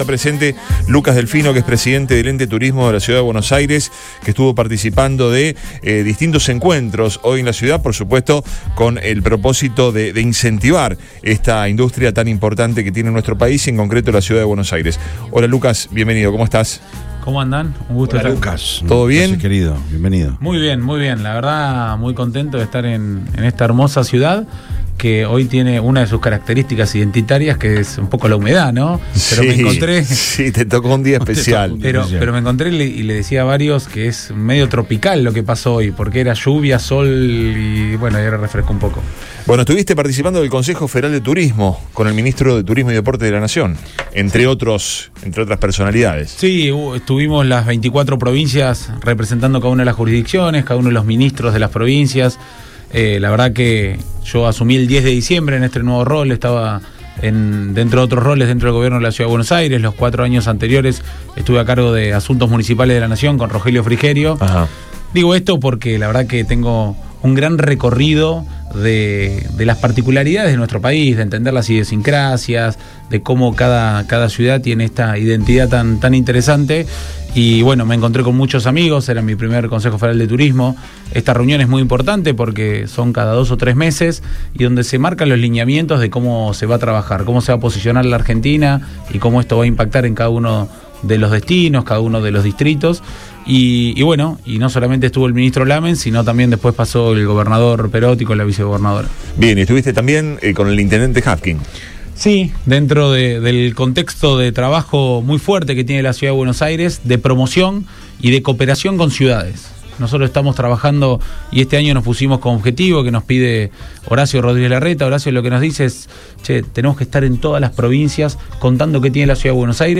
Luego del evento, que contó con la presencia del gobernador Omar Perotti, el presidente del Ente de Turismo de Buenos Aires, Lucas Delfino, pasó por los estudios de Radio Boing.